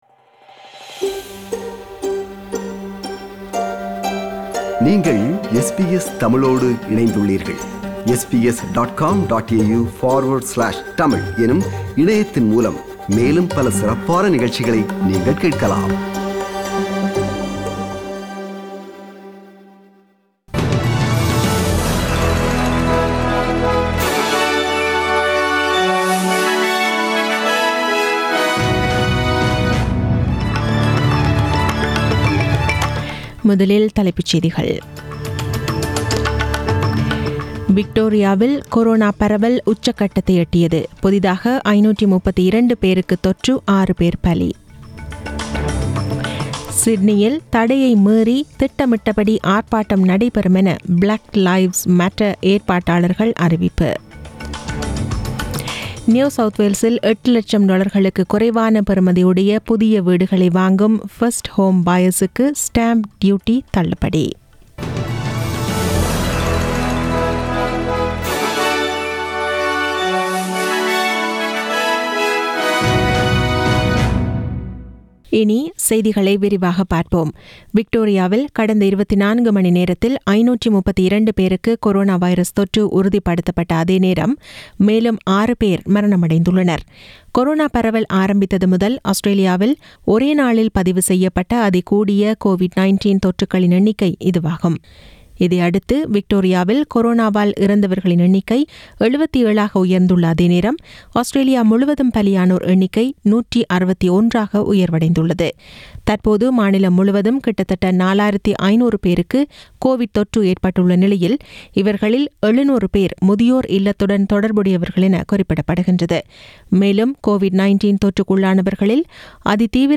The news bulletin aired on 27 July 2020 at 8pm